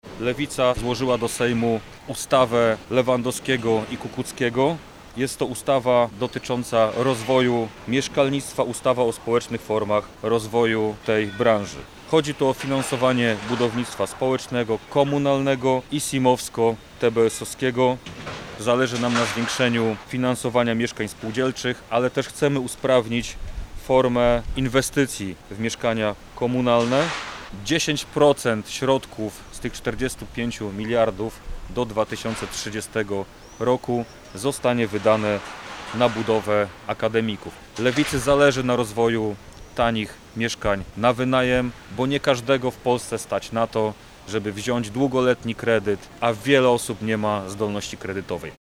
Mówi Dominik Kłosowski, radny miejski Wrocławia i sekretarz Nowej Lewicy we Wrocławiu.